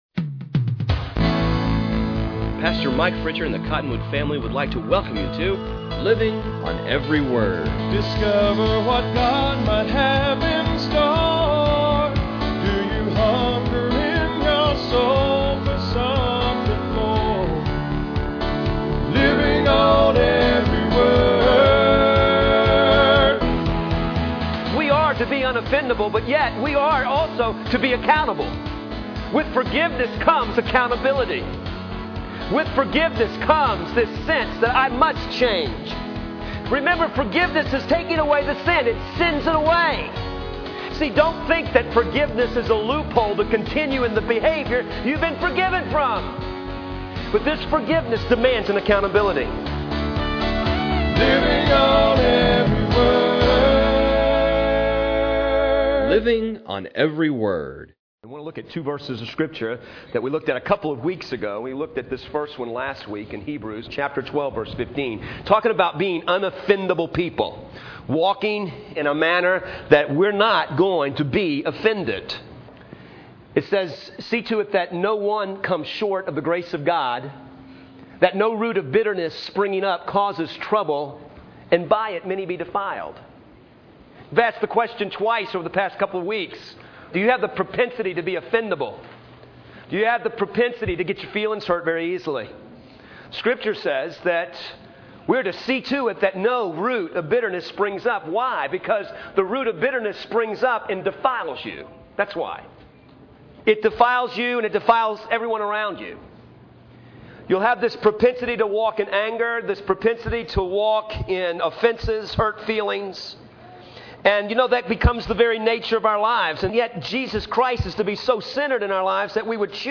In today's sermon